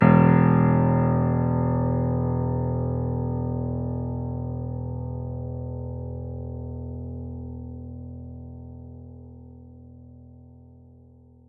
piano-sounds-dev
Vintage_Upright
e0.mp3